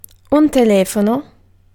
Ääntäminen
IPA: [te.le.fɔn]